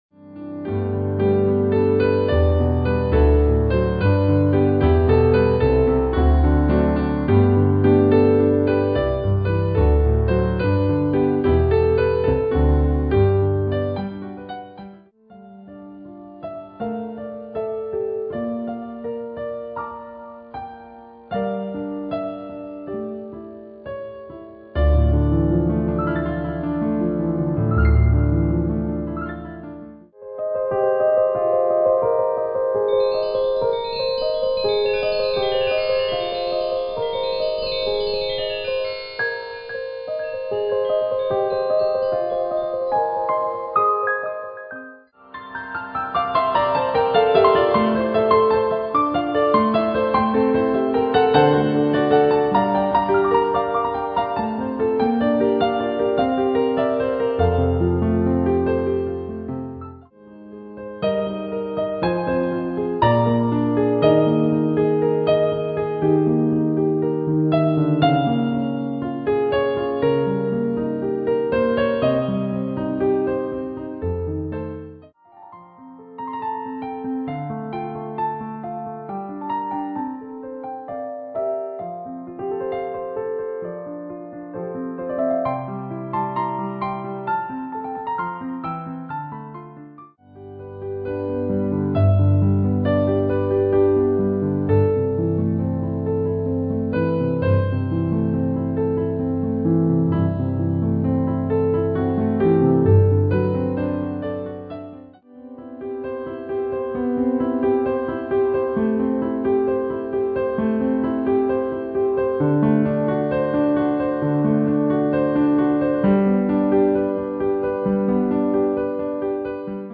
An enchanting evening of music
Instrumental
Solo Instrument